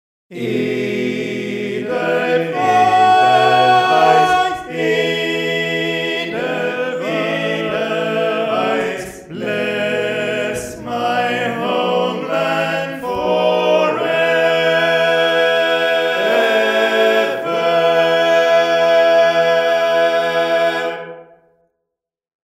Key written in: E Major
How many parts: 4
Type: Barbershop
All Parts mix: